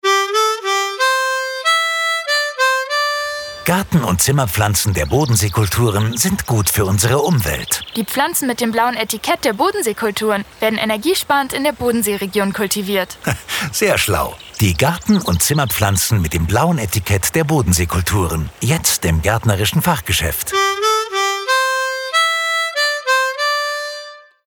Unser Hörfunkspot